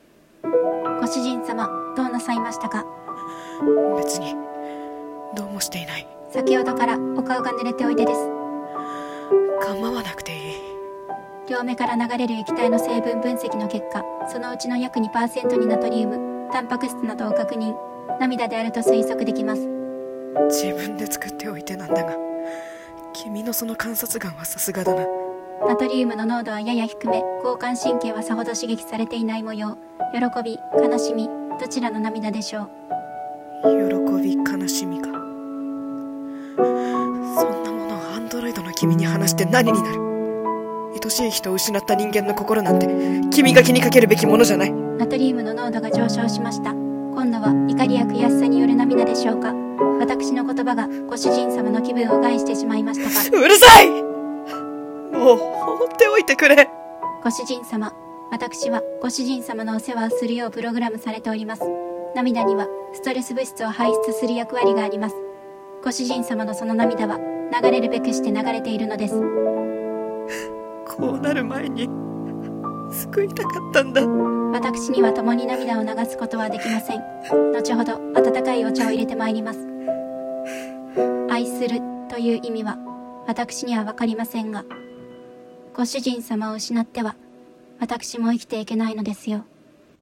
声劇台本 掛け合い 『泣キタイ機械』